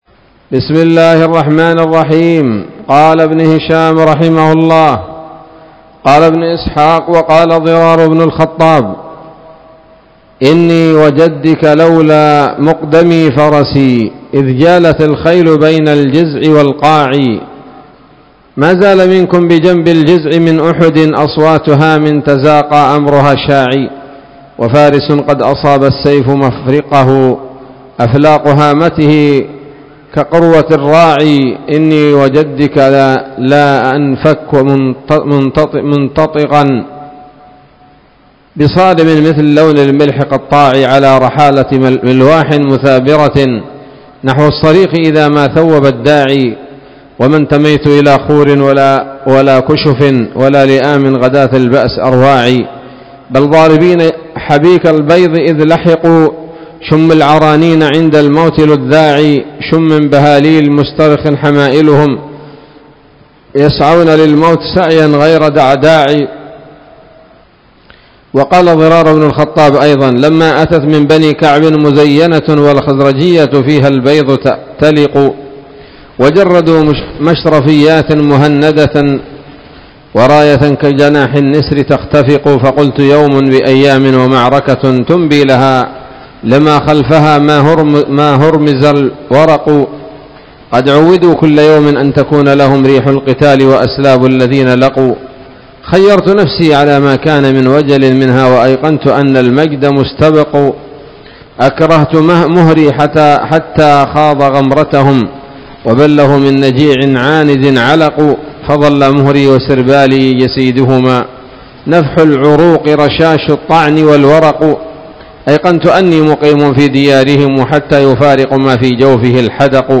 الدرس الثمانون بعد المائة من التعليق على كتاب السيرة النبوية لابن هشام